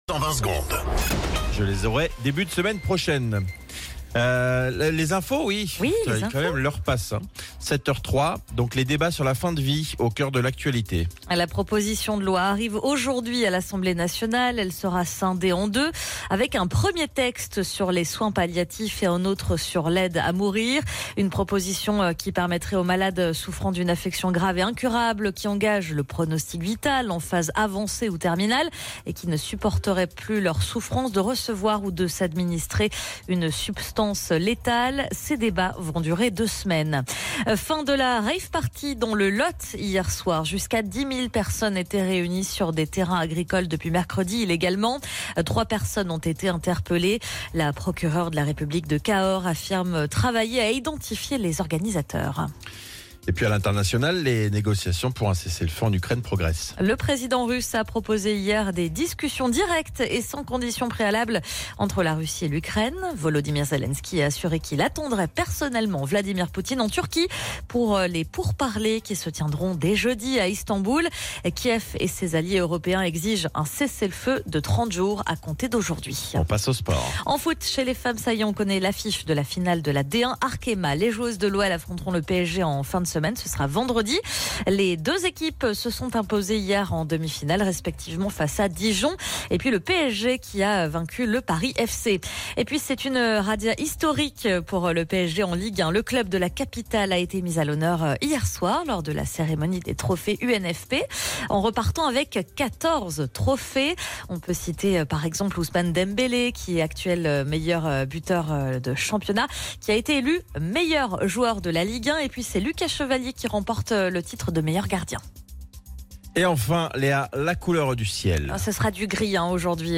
Flash Info National 12 Mai 2025 Du 12/05/2025 à 07h10 .